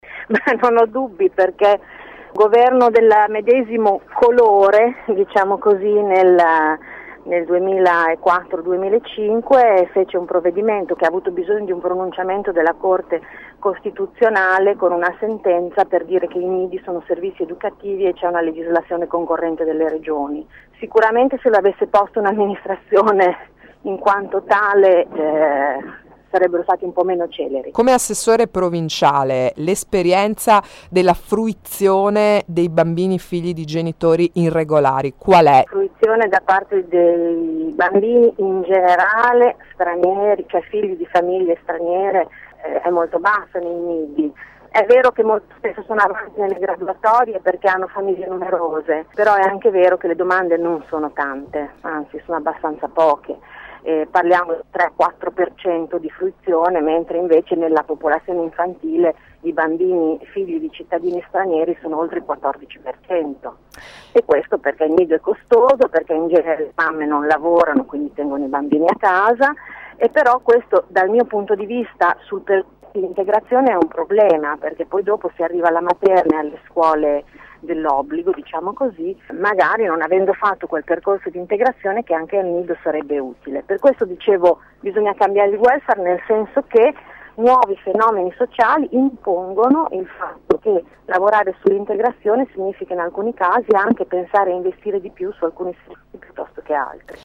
14 apr. – E’ l’opinione dell’assessore provinciale alla scuola Anna Pariani che questa mattina è stata ospite di AngoloB.
Ascolta l’assessore Pariani